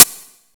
Hat (53).wav